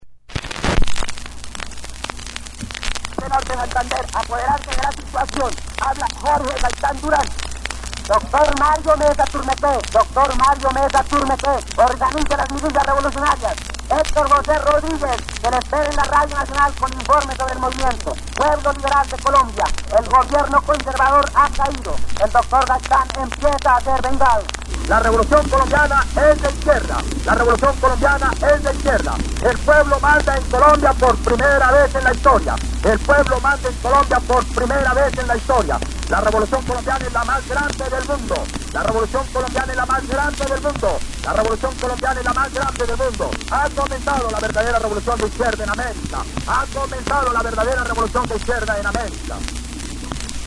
Luego de haber sido asesinado Jorge Eliécer Gaitán la turba de rebelados se tomó la radio con el fin de unir fuerzas y hacerse al poder, las grabaciones radiales que quedaron -que conserva nuestra marca hermana, Señal Memoria- son verdaderamente impactantes. En ellas quedó registrado un episodio de nuestra historia cargado de emociones: furor, deseo de venganza y cambio, también hay algo de ilusión, quizá esperanza.